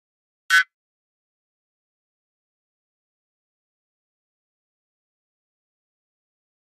Flash Alarm High Frequency Electronic Buzz